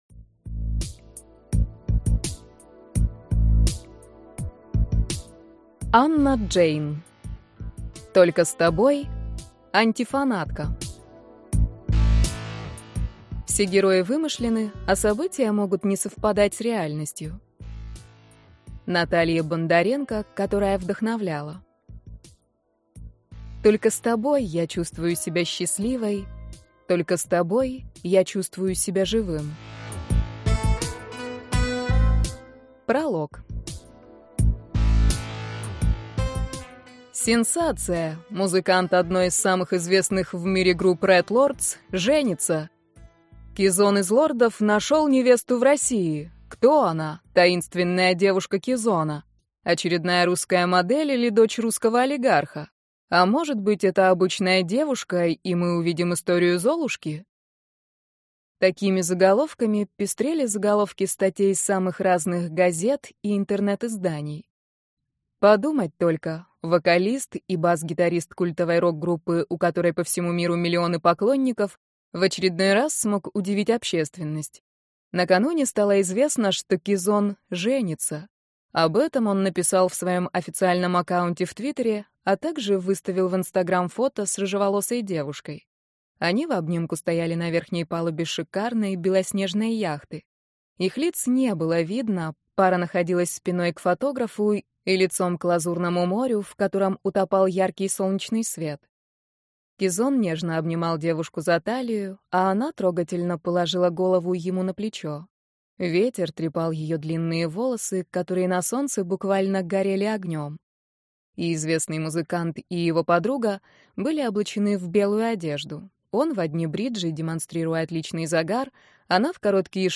Аудиокнига Только с тобой. Антифанатка | Библиотека аудиокниг